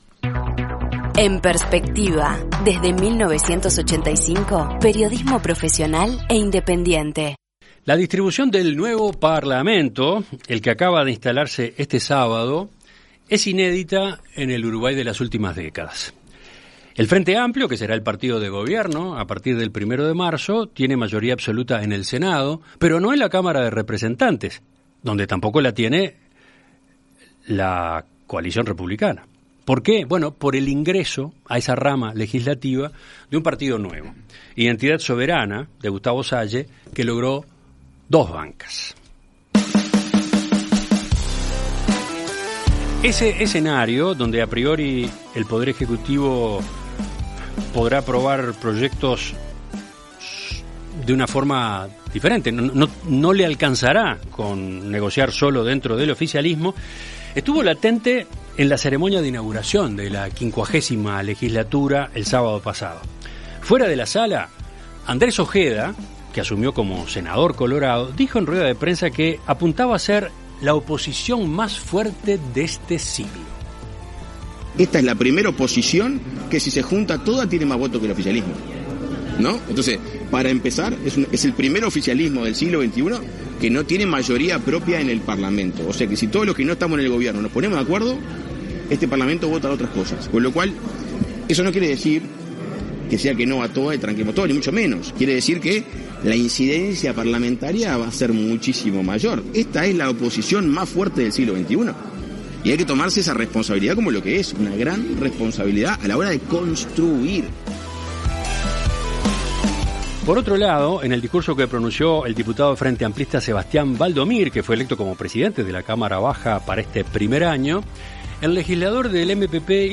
En Perspectiva Zona 1 – Entrevista Central: Mariano Tucci y Conrado Rodríguez - Océano